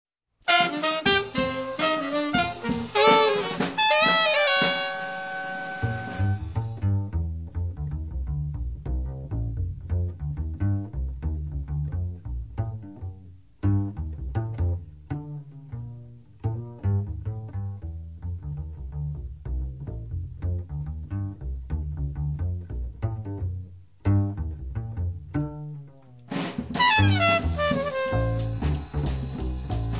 • free jazz
• jazz moderno